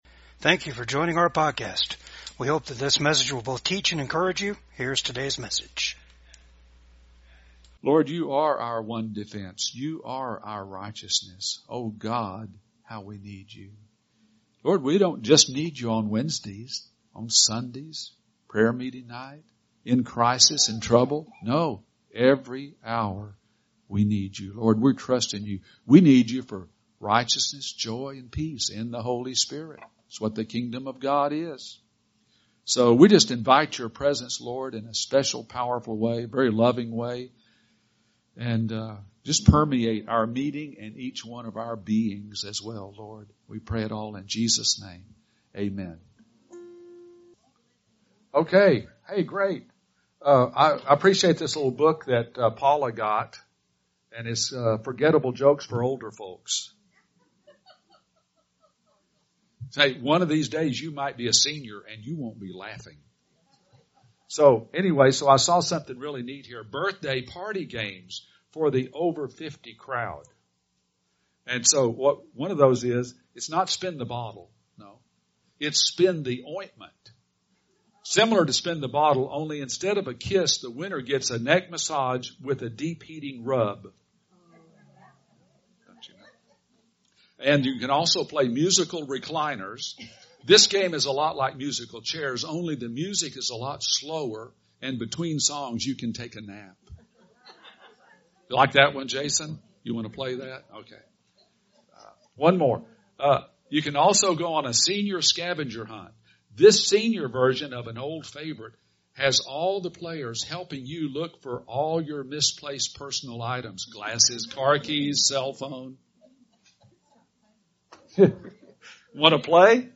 Service Type: VCAG WEDNESDAY SERVICE